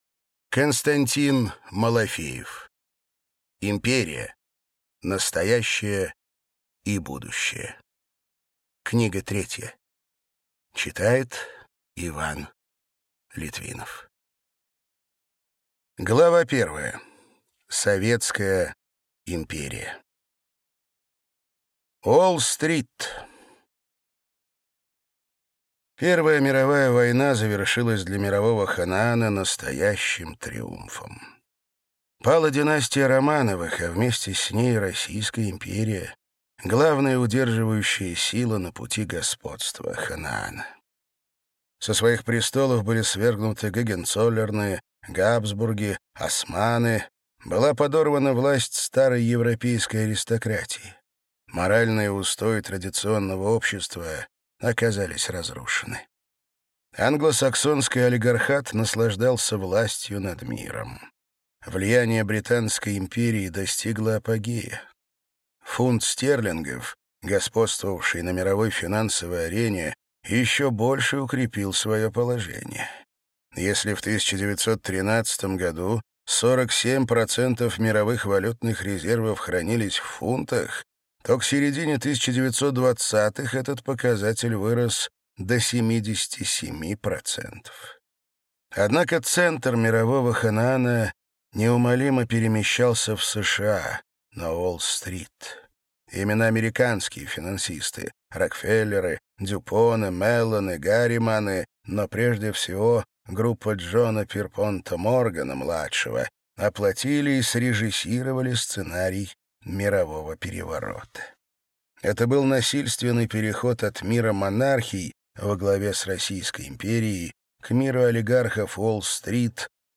Аудиокнига Империя. Настоящее и будущее. Книга 3. Часть 1 | Библиотека аудиокниг